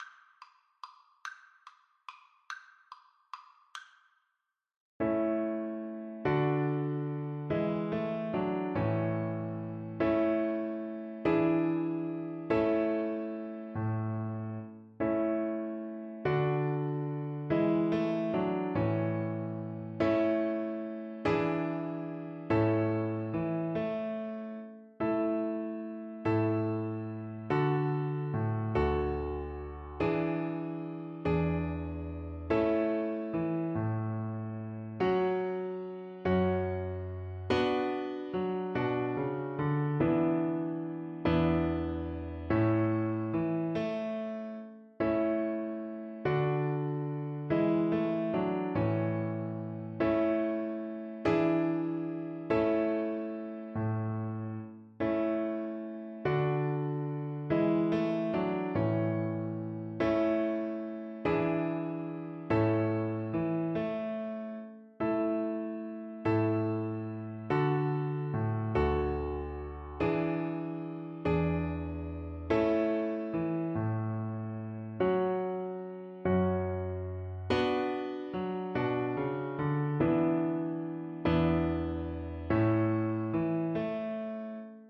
Violin
A major (Sounding Pitch) (View more A major Music for Violin )
6/8 (View more 6/8 Music)
Allegro = c. 112 (View more music marked Allegro)
Traditional (View more Traditional Violin Music)